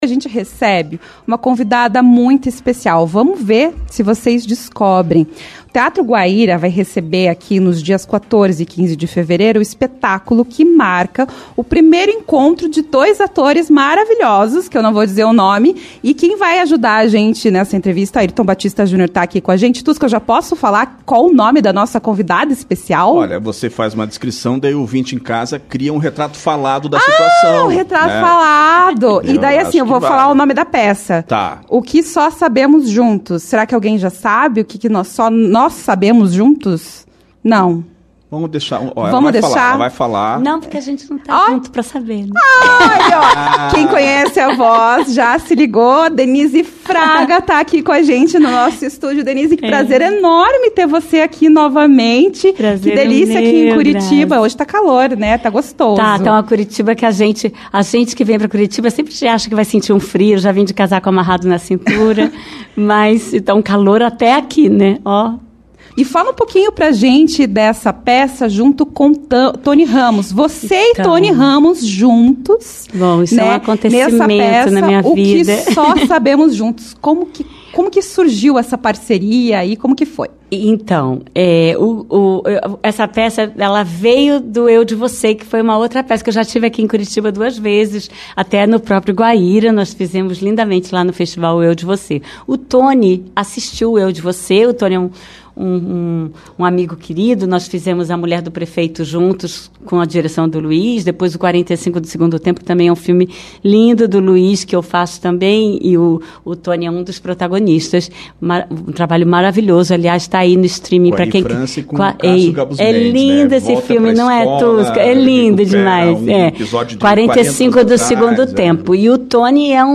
Denise Fraga foi ao estúdio da CBN Curitiba
CBN Curitiba 2ª Edição. Na entrevista, a atriz falou sobre a sua peça com Tony Ramos, O que só sabemos juntos, além de abordar o teatro de maneira geral.